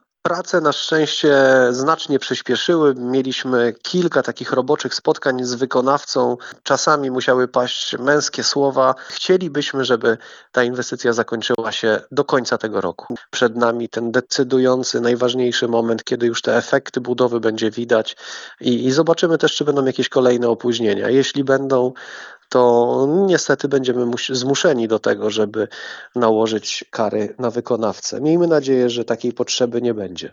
O sytuacji mówi zastępca prezydenta Szczecina, Marcin Biskupski: